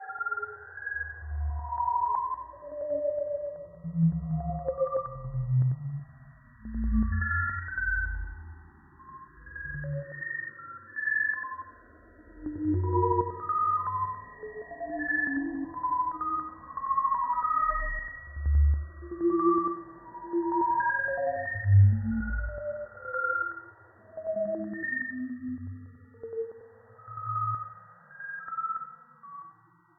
صداهایی ترسناک و مخوف از اعماق کائنات
فهرست این صداهای هالووینی که به Soundcloud ارسال شده است، پُر از ناله و شیون های ترسناک است.